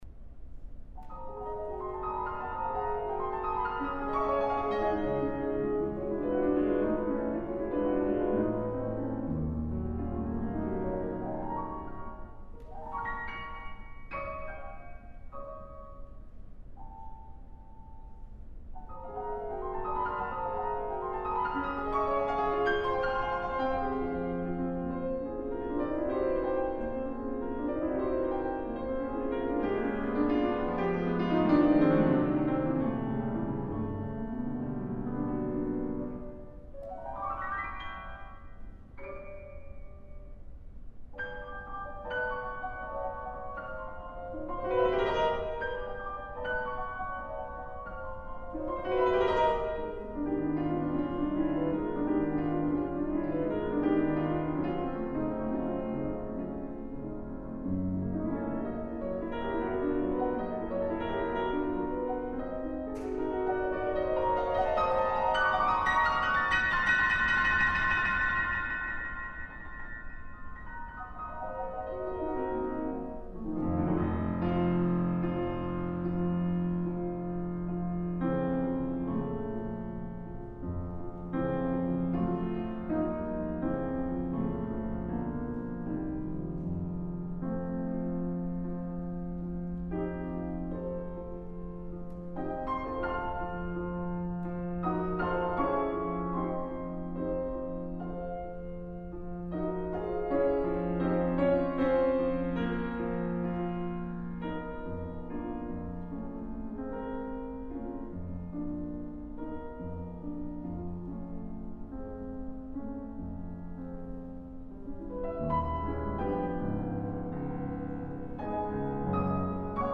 Concert hall of Aichi University of the Arts (Japan).